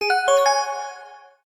menuhit.mp3